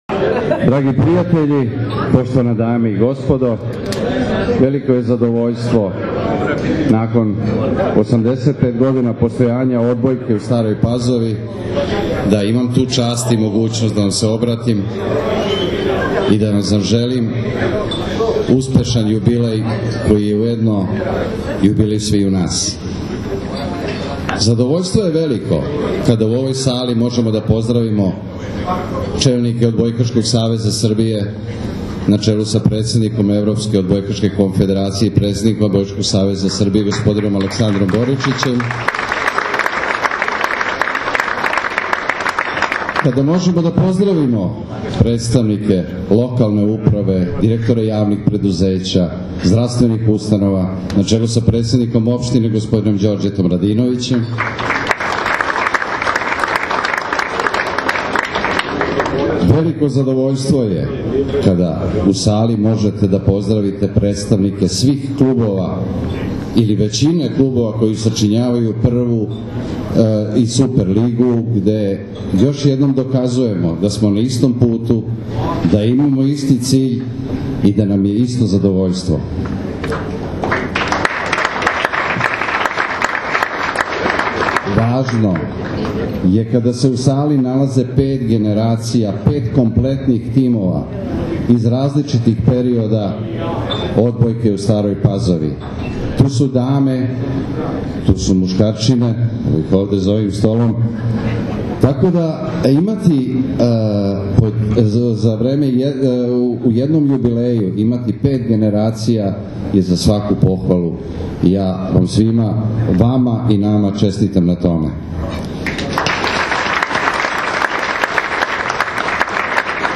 Odbojkaški klub “Jedinstvo” iz Stare Pazove proslavio je sinoć 85 godina postojanja na svečanosti održanoj u hotelu “Vojvodina” u Staroj Pazovi.
OBRAĆANJE